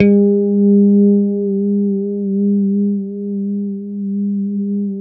A3 PICKHRM2A.wav